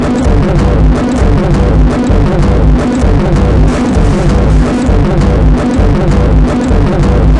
节奏2
描述：声音 噪声 样本 音频 咆哮 现场录音 节奏 buhddist 寺庙现场
Tag: 弯曲 电路 记录 节奏 搅动 样品 音频 声音 噪音